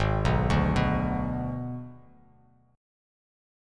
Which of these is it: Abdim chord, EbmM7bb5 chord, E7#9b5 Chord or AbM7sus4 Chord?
Abdim chord